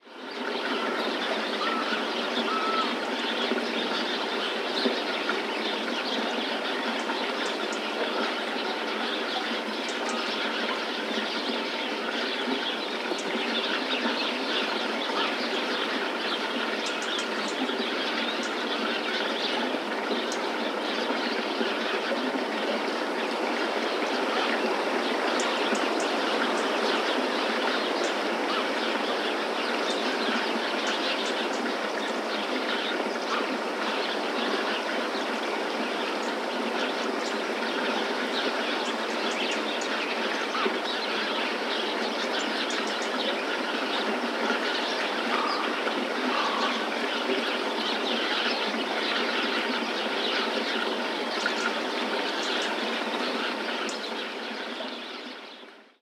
Ambiente de pájaros 2
pájaro
Sonidos: Animales
Sonidos: Rural